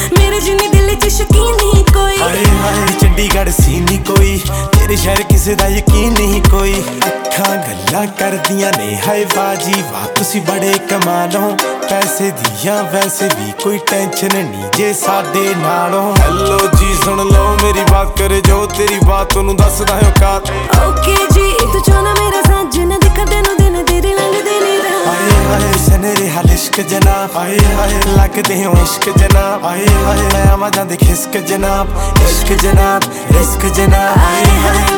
Жанр: Поп / Инди / Местная инди-музыка